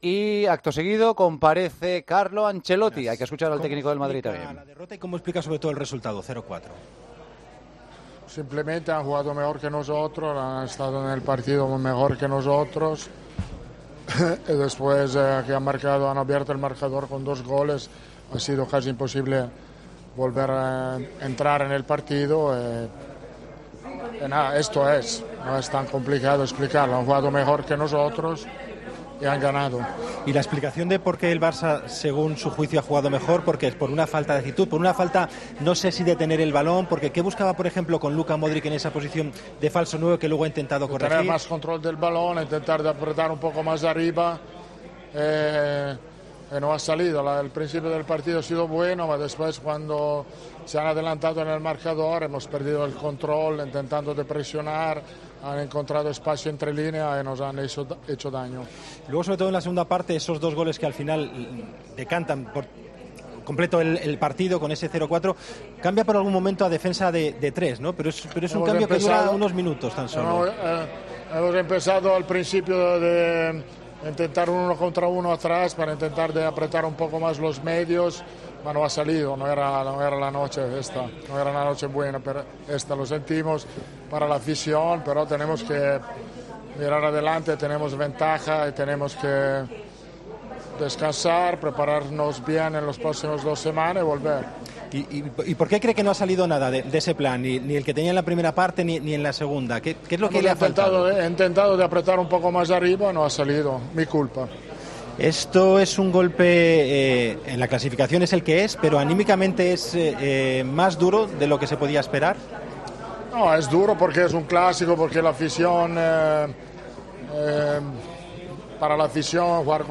"El planteamiento no ha sido bueno y no ha salido bien, no lo he planteado bien para ganar el partido. El Barcelona ha sido mejor y ha merecido ganar, no tengo problemas en asumir la responsabilidad porque he fallado en este partido", aseguró en rueda de prensa.